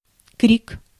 Ääntäminen
IPA: /krʲik/